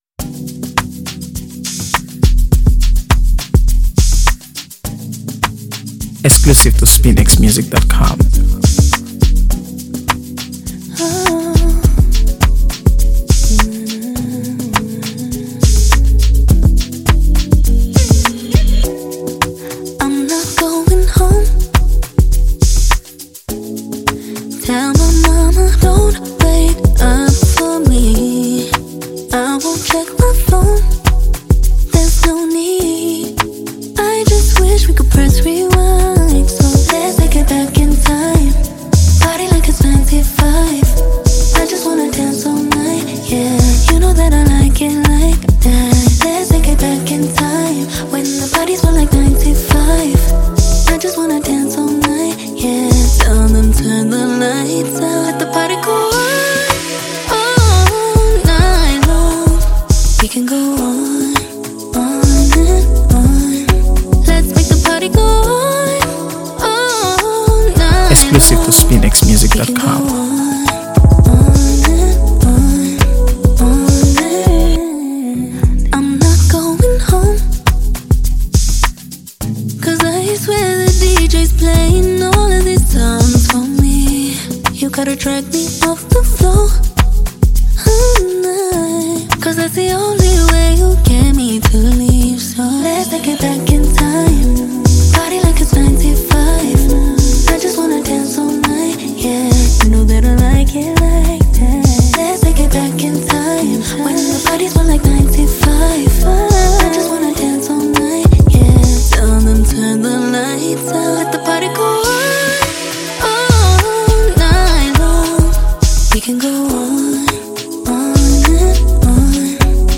Grammy-winning South African singer-songwriter